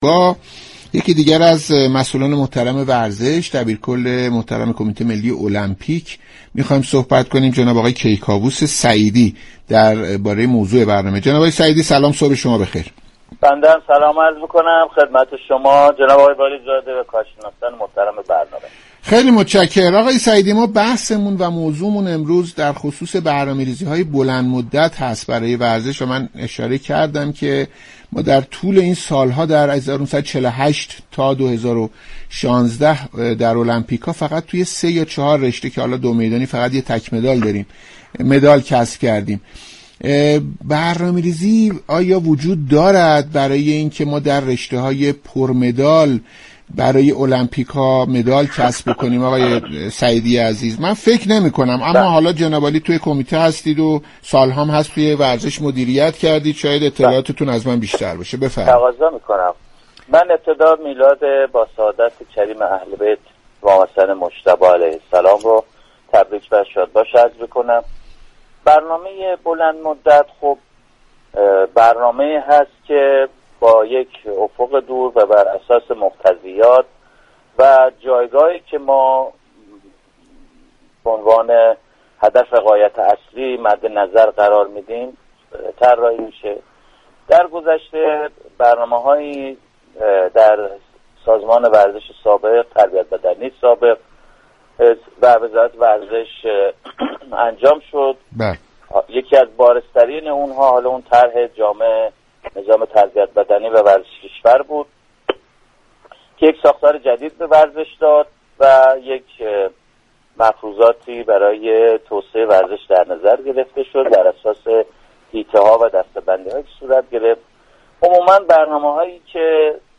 برنامه «صبح و ورزش» شنبه 20 اردیبهشت در گفتگو با كیكاووس سعیدی، دبیركل كمیته ملی المپیك به موضوع برنامه ریزی های بلندمدت برای موفقیت در المپیك ها پرداخت.